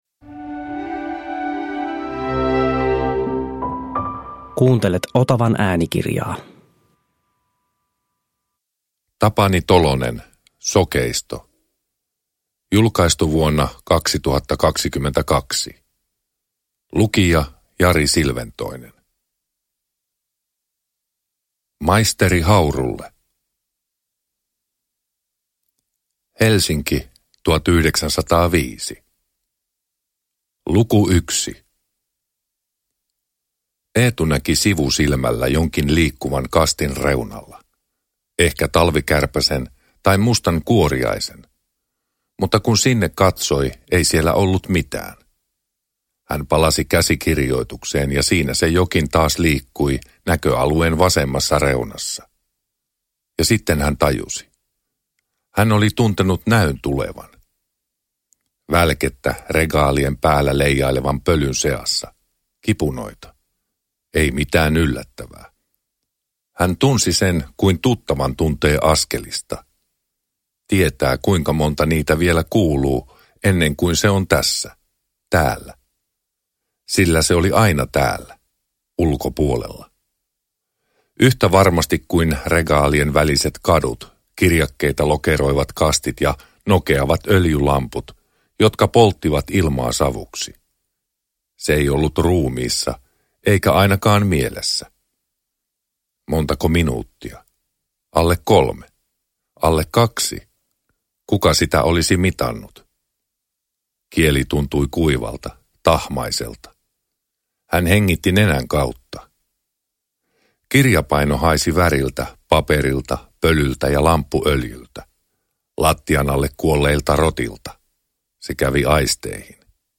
Sokeisto – Ljudbok – Laddas ner